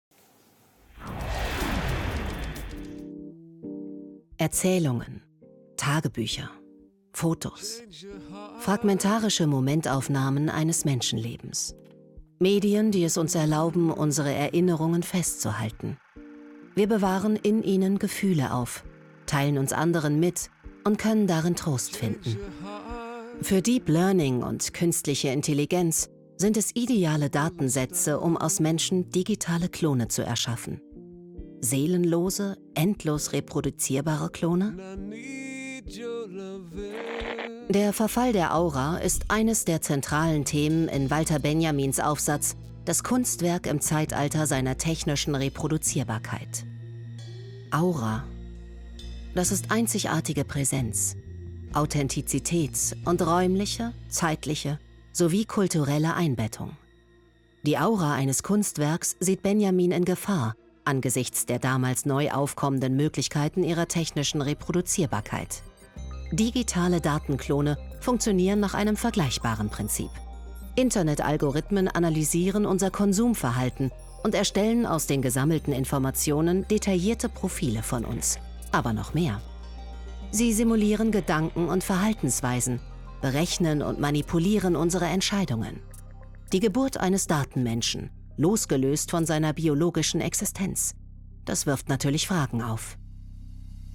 TV – Kommentar/Voice over:
Kommentar – getragen